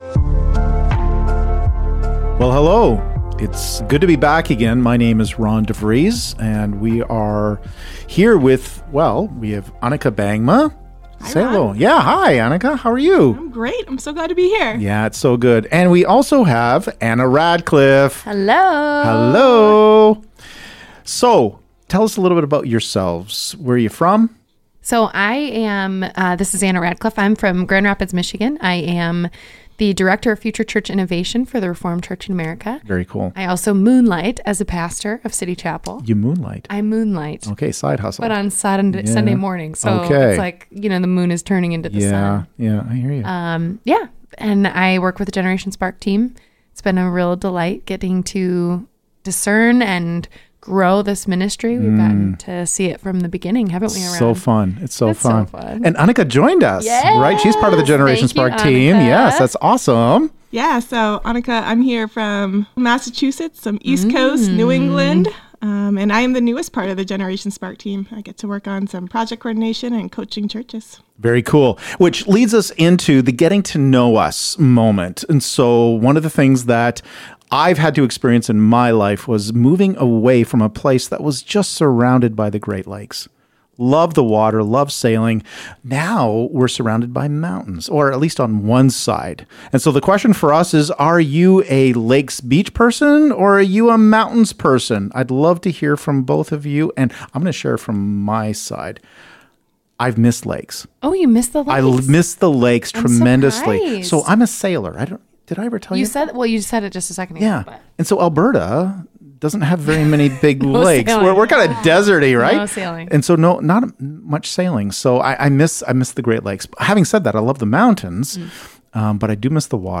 Connecting life, theory, practice and ministry with an intergenerational conversation.